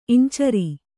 ♪ iñcari